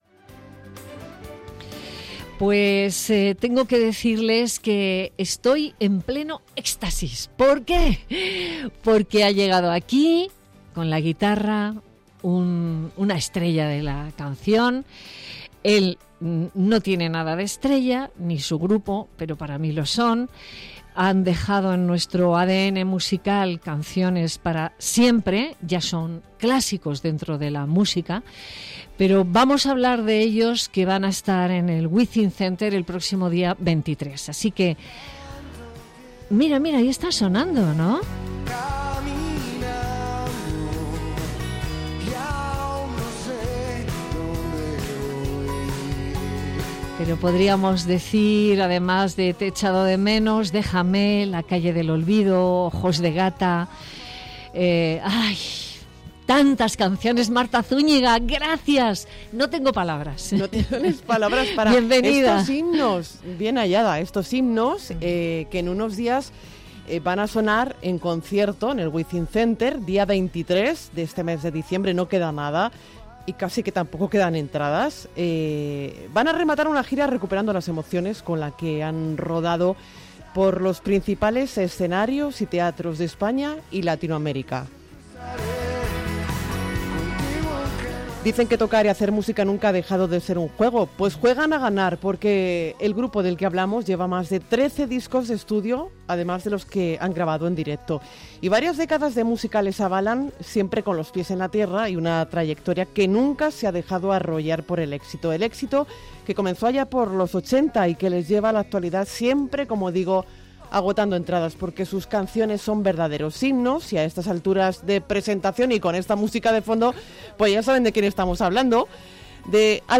El cantante Álvaro Urquijo, del grupo Los Secretos, ha estado charlando con Nieves Herrero en Madrid Directo acerca del final de su gira. El día 23 de diciembre culminan Recuperando las emociones con un concierto en el WizinkCenter tras visitar los principales escenarios y teatros de Latinoamérica y España.